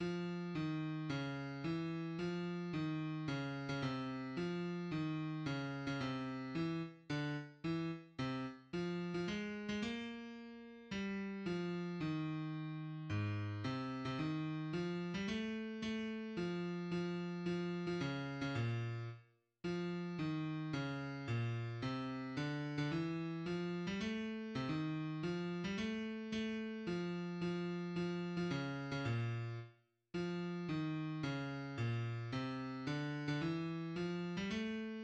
sheet music
third verse